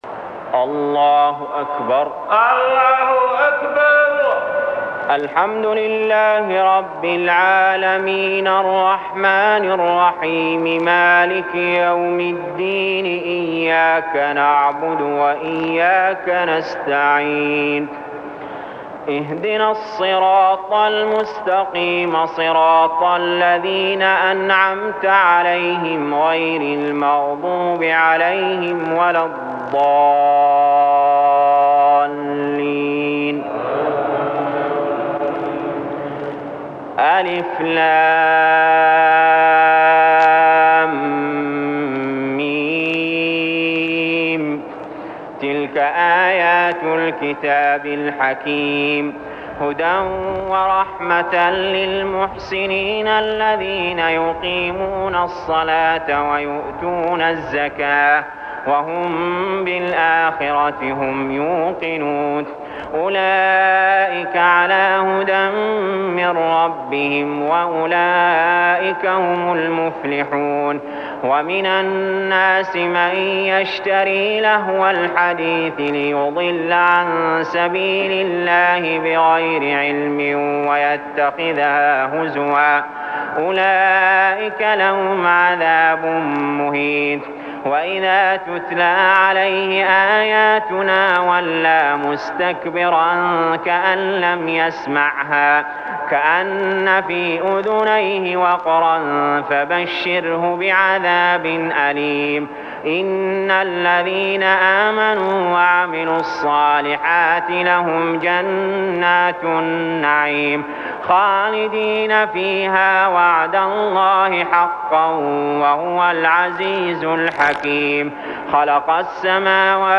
المكان: المسجد الحرام الشيخ: علي جابر رحمه الله علي جابر رحمه الله لقمان